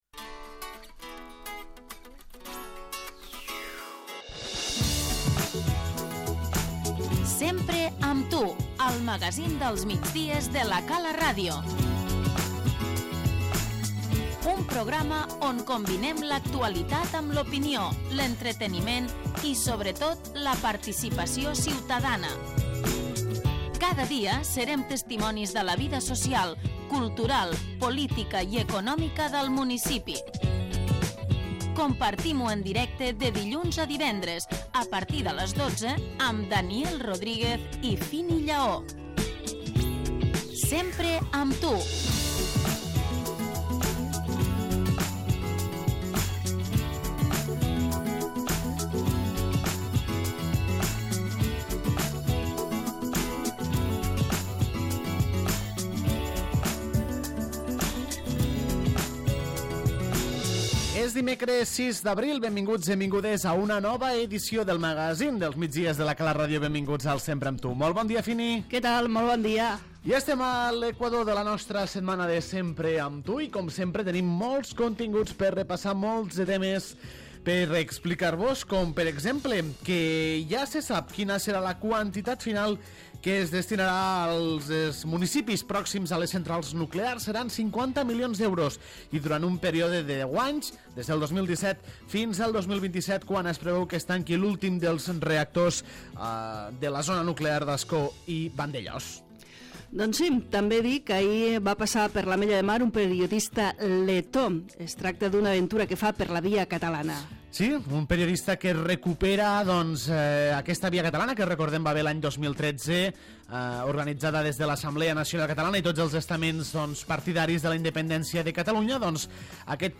Benvingudes i benvinguts a un nou Sempre amb tu, el magazín del migdia de La Cala RTV.
Mayte Puell, Regidora d'Esports, ens dóna detalls, a l'Entrevista, del Dia Mundial de l'Activitat Física.